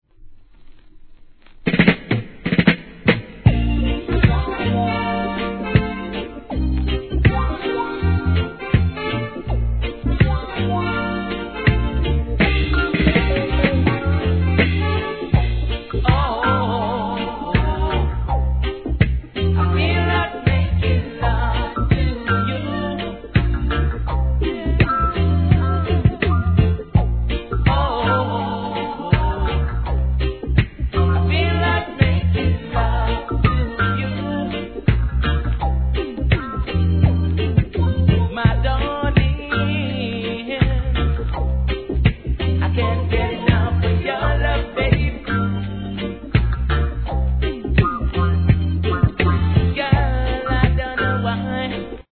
REGGAE
君の事をまだ十分に分かってないんだ、もっと知りたいんだ♪と見事なバック・コーラスで求愛☆